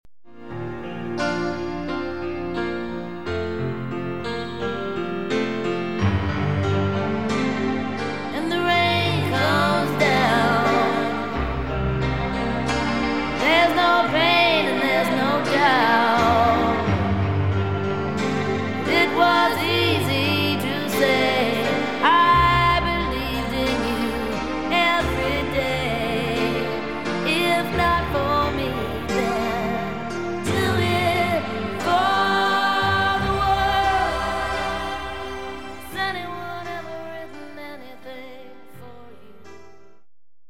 a few short sound bites